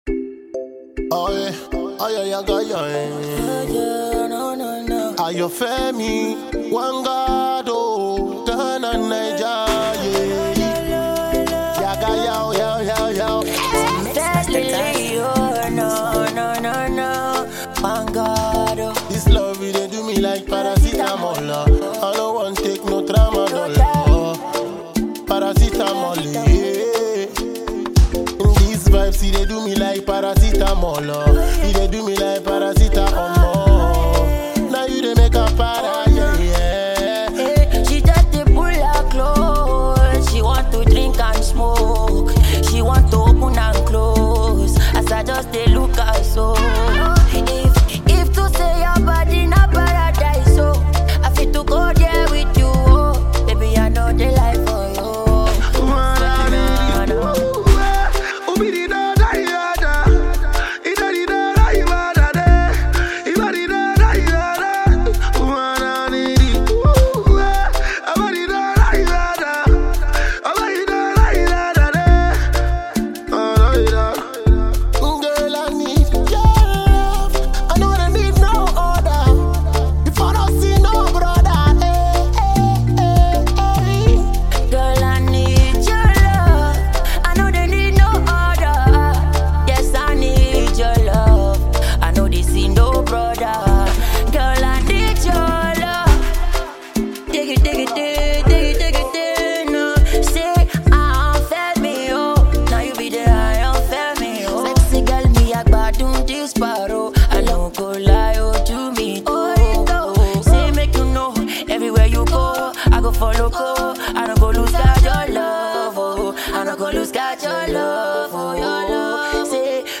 enjoy the rhythmic composition and the vibe.